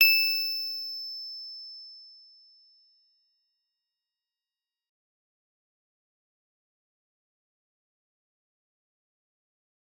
G_Musicbox-E7-f.wav